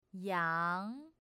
Pronounce "goat" in Chinese
yáng in Chinese, with a rising second tone.